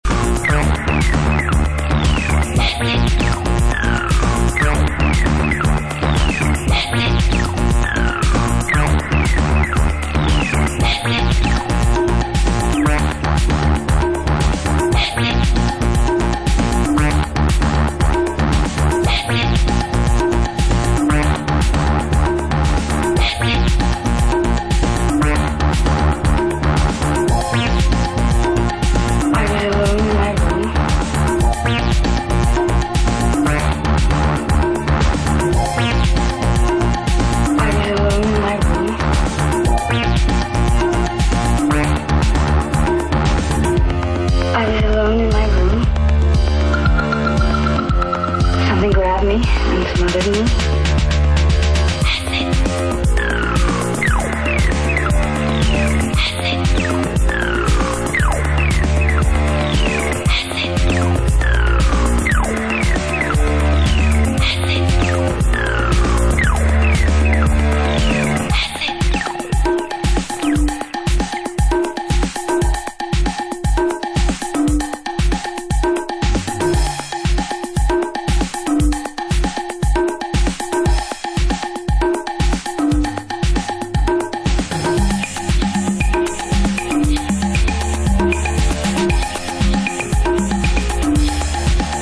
Dark & very serious new beat classic, still sounding fresh.